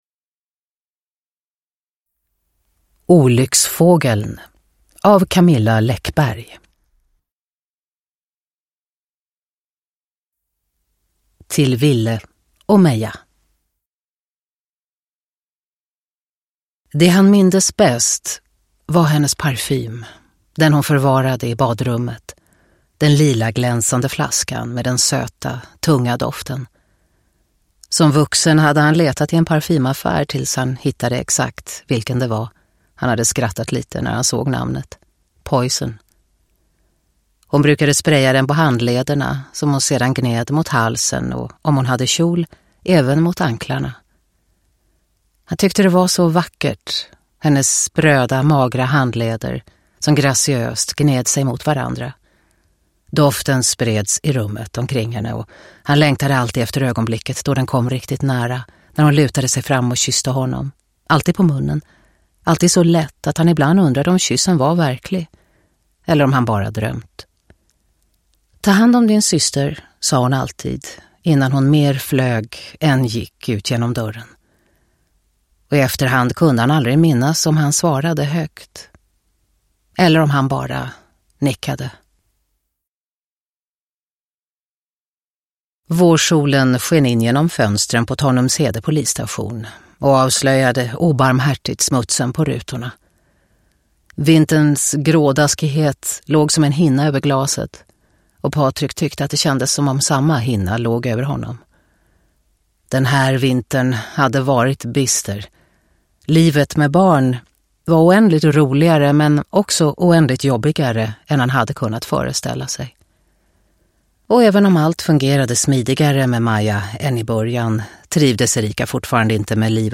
Uppläsare: Katarina Ewerlöf
Ljudbok